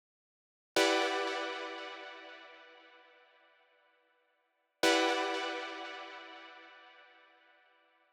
12 Synth PT2.wav